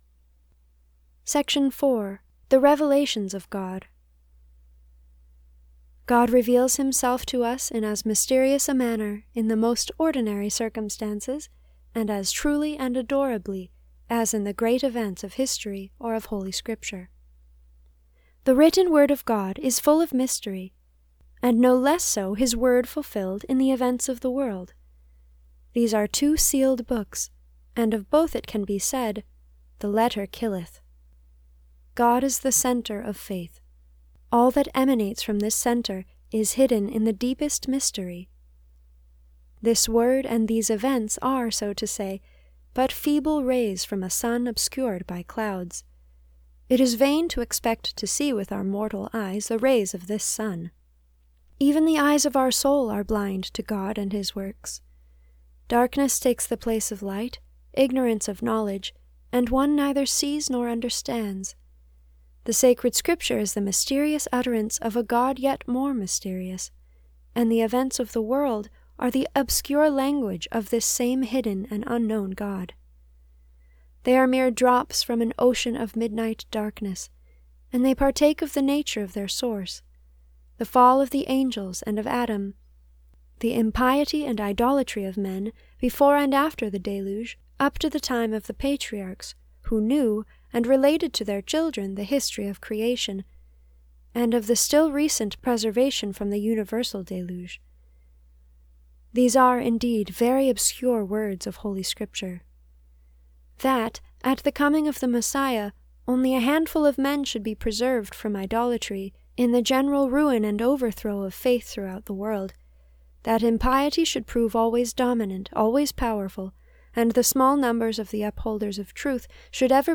This is a reading from the spiritual classic Abandonment to Divine Providence by Jean Pierre de Caussade (1675 - 1751).